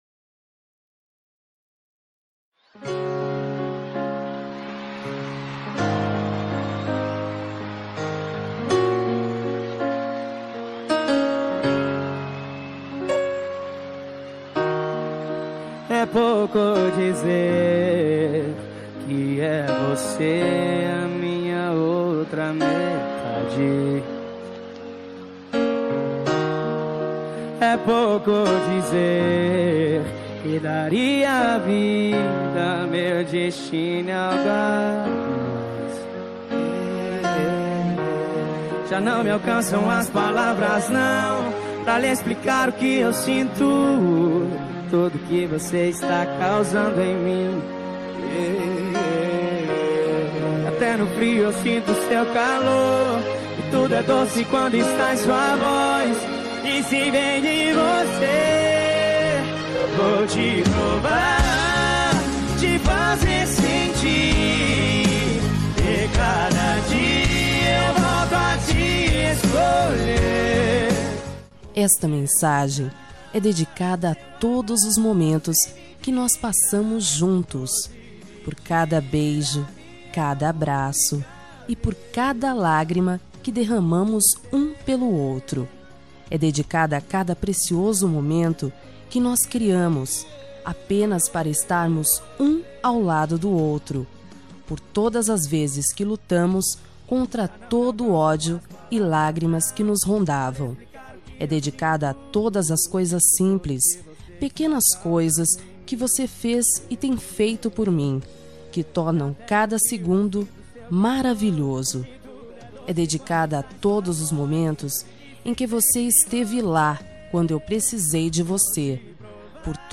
Telemensagem Romântica Lindaaa – Voz Feminina – Cód: 8083